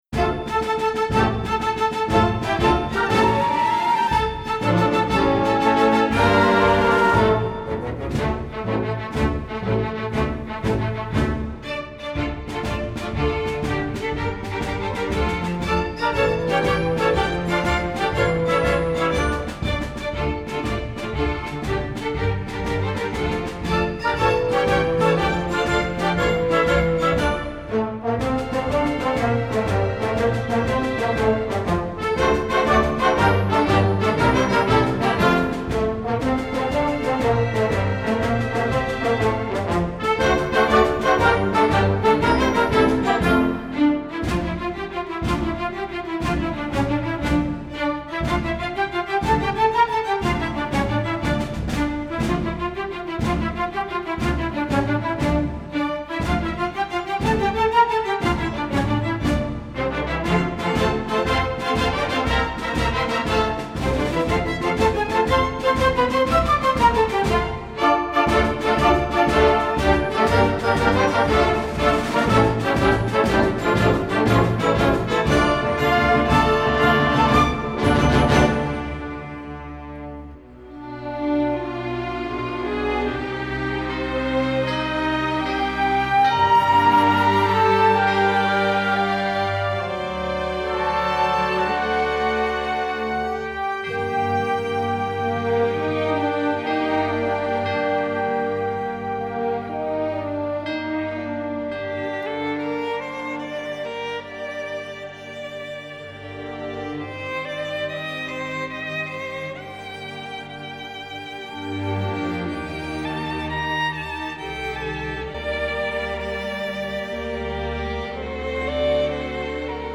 Composer: Traditional
Voicing: String Orchestra